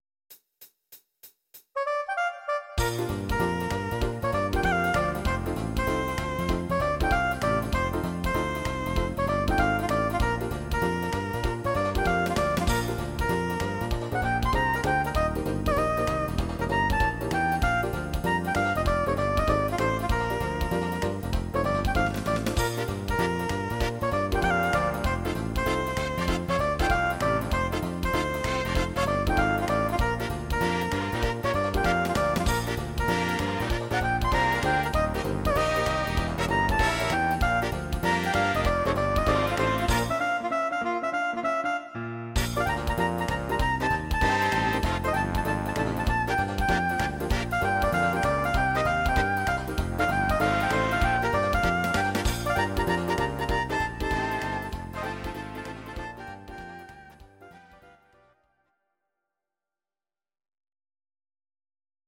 These are MP3 versions of our MIDI file catalogue.
Please note: no vocals and no karaoke included.
Your-Mix: Jazz/Big Band (731)
instr. Saxophon